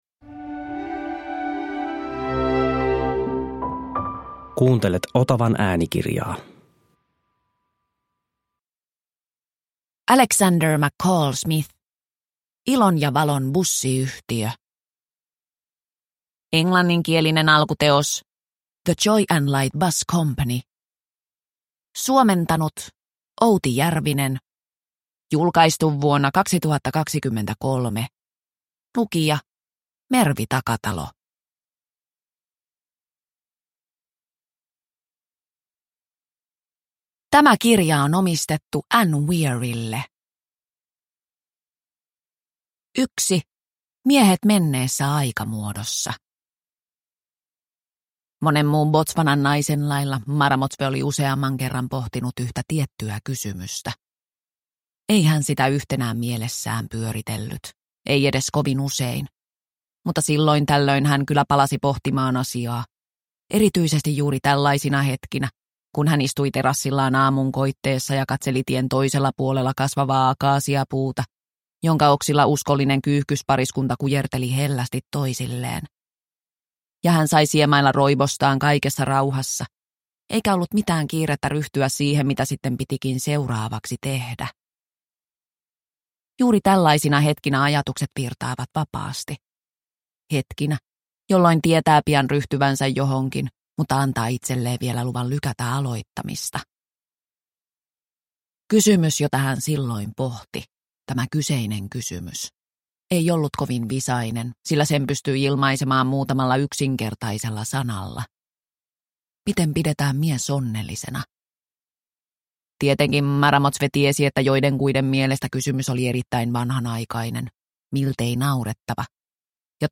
Ilon ja valon bussiyhtiö – Ljudbok – Laddas ner